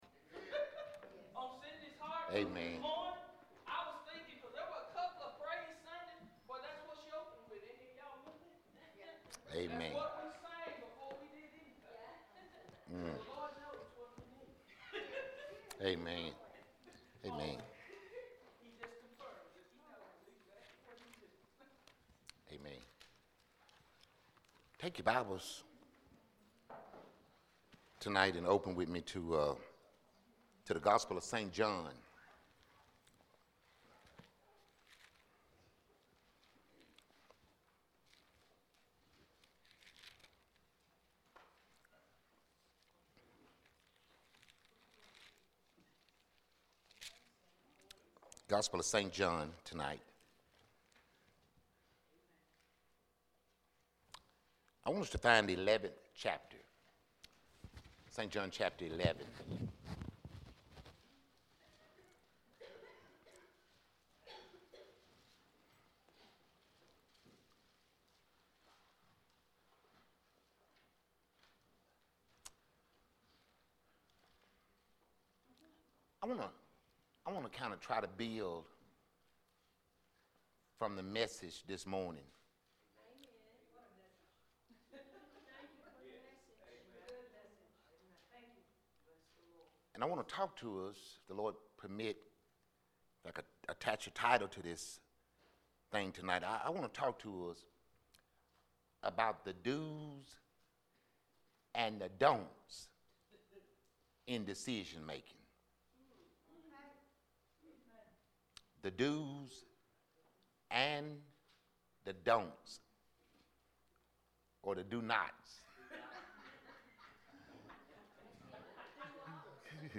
Solid Rock Baptist Church Sermons
Jan 7 2018EveningService.mp3